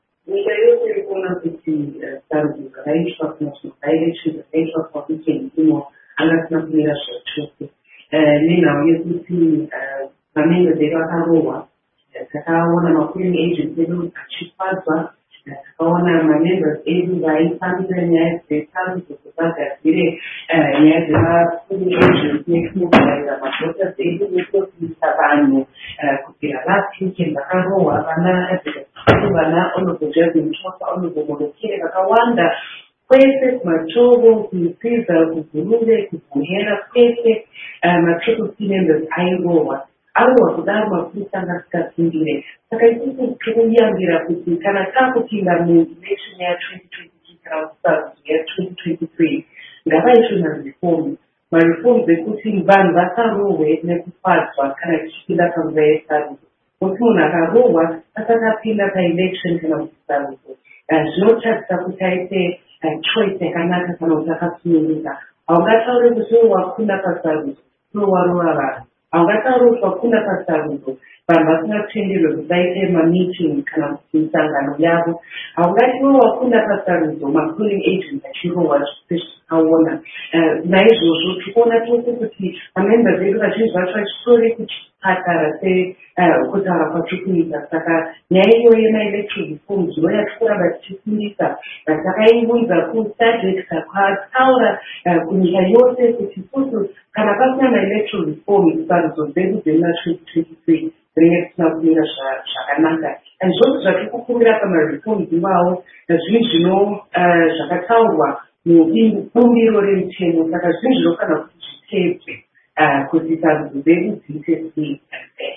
Hurukuro naMuzvare Fadzayi Mahere.mp3